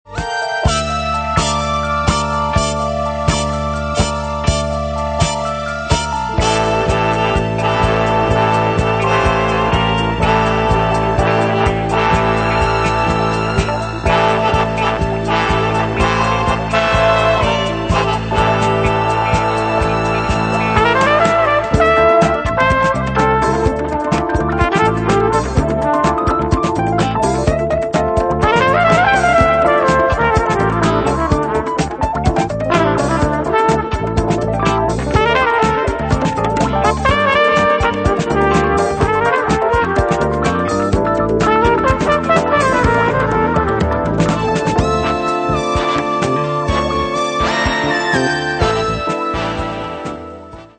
22 groovy tv & movie themes from 1969-1984